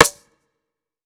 Soulful Snare.wav